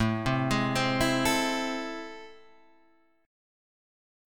A Minor Major 7th